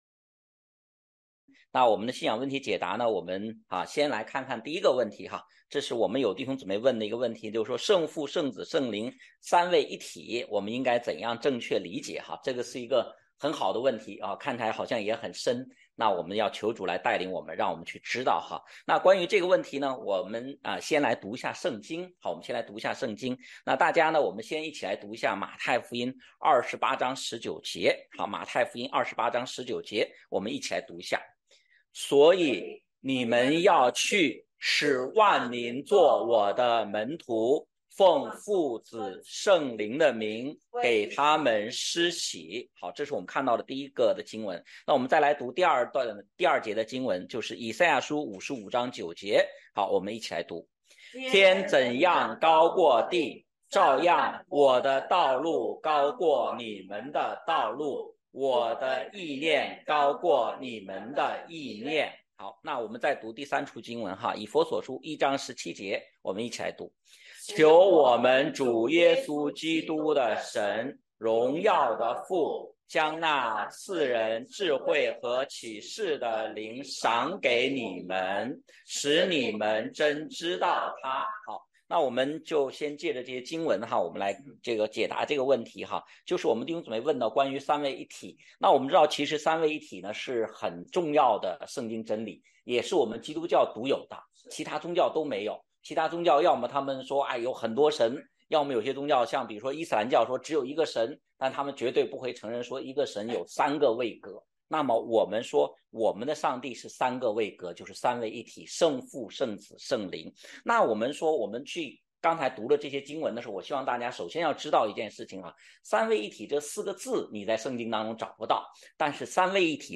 问题解答录音